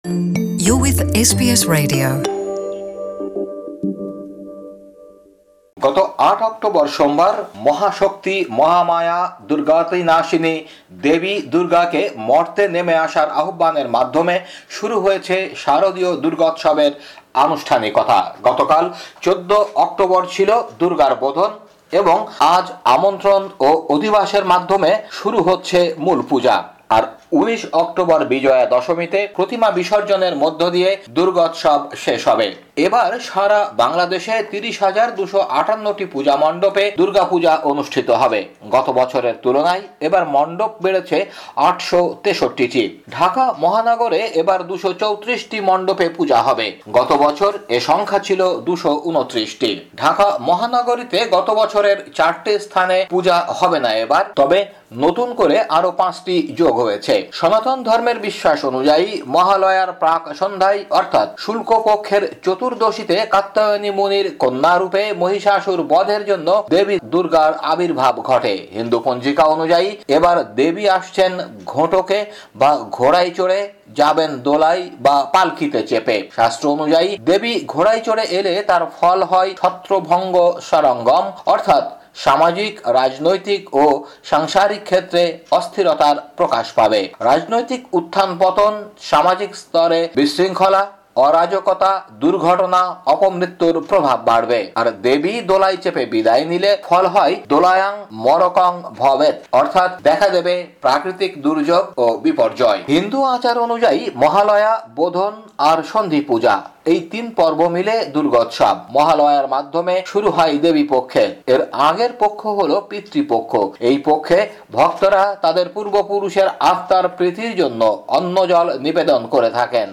প্রতিবেদনটি বাংলায় শুনতে উপরের অডিও প্লেয়ারটিতে ক্লিক করুন।